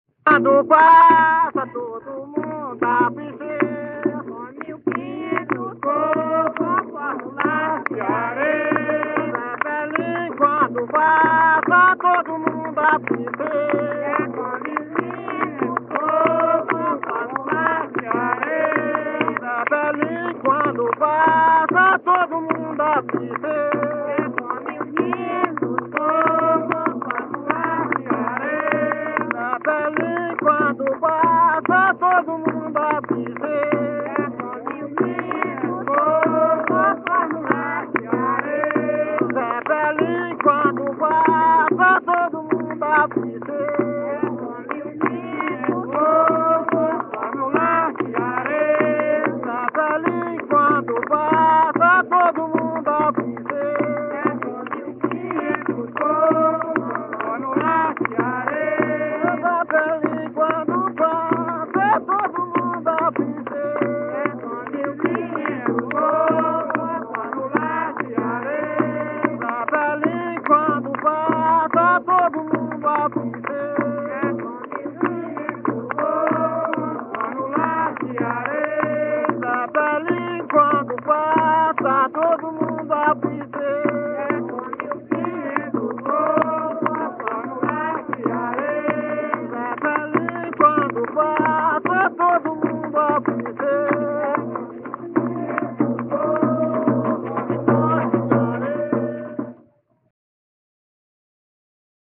Coco de parelha – “”Zepelin quando passa”” - Acervos - Centro Cultural São Paulo